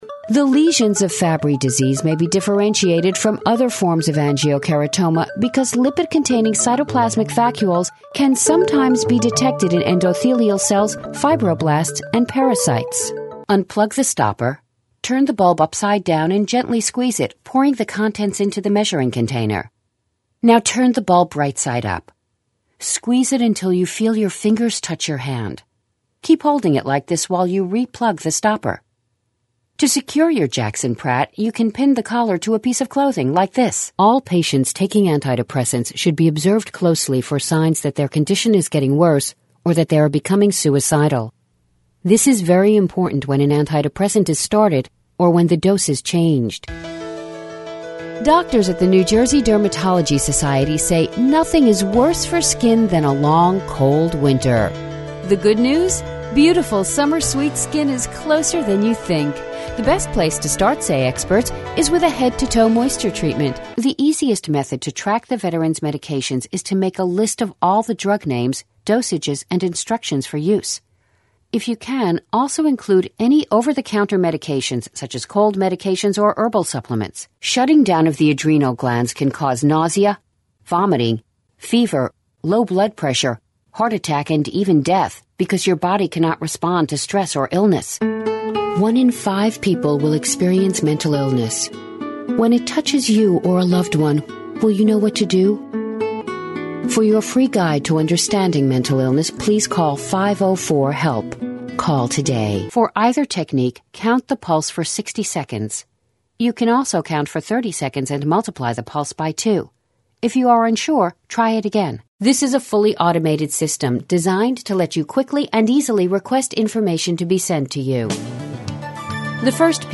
Full-time with professional ISDN equipped studio. Powerful intelligent reads.
Bilingual Female Voiceover Talent w/ISDN.
Sprechprobe: eLearning (Muttersprache):